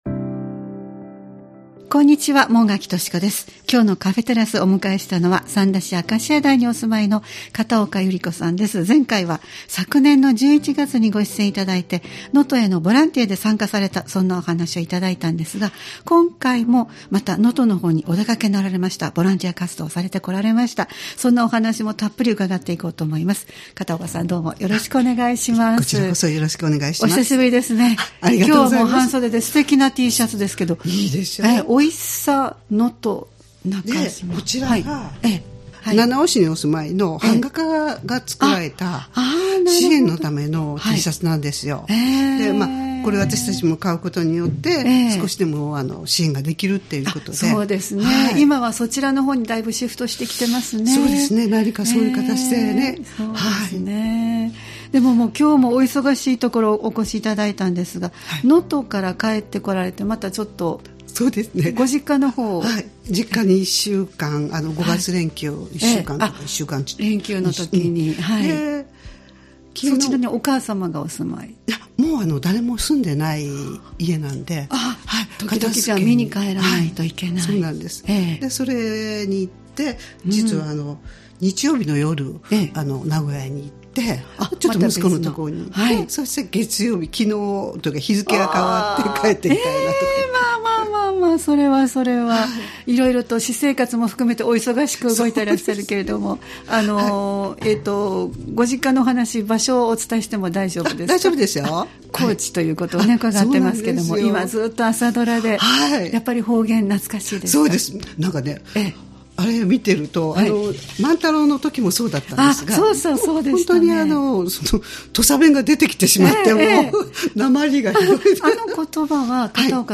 様々なジャンルで活動・活躍されている方をお迎えしてお話をお聞きするポッドキャスト番組「カフェテラス」（再生ボタン▶を押すと放送が始まります）